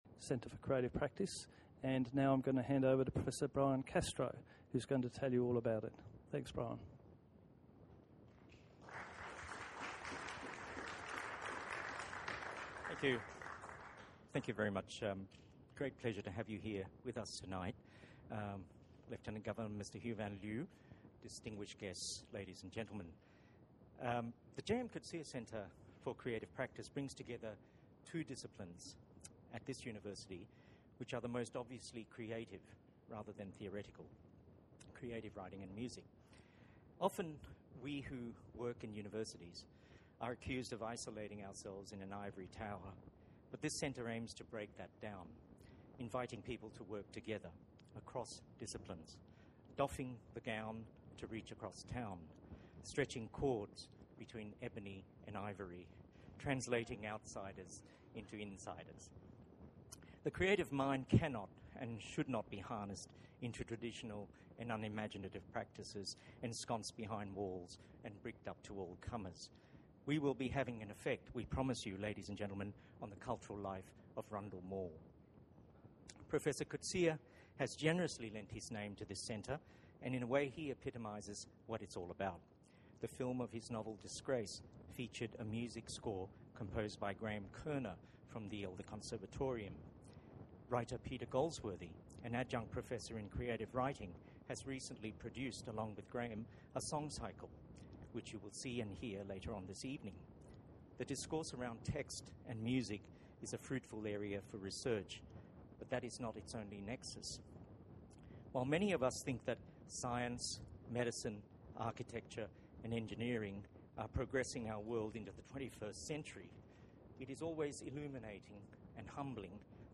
Listen to the mp3: Brian Castro's and J. M. Coetzee's speech.
castro-jmc-speech.mp3